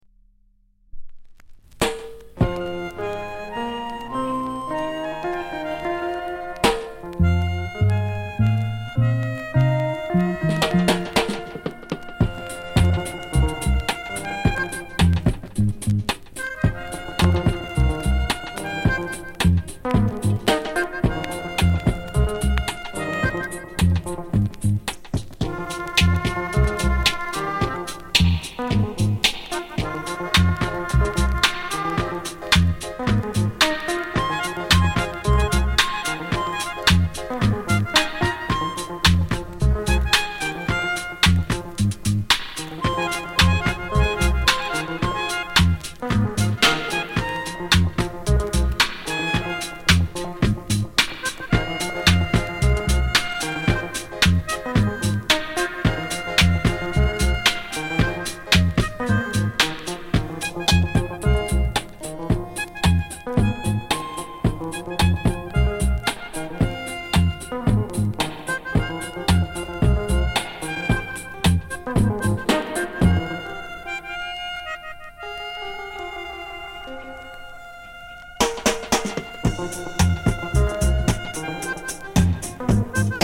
JA FUNK～RARE GROOVE！